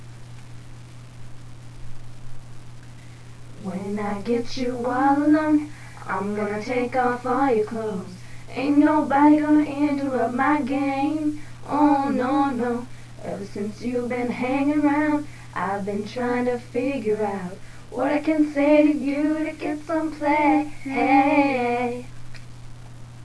Please Note...they Do NOT Play Instruments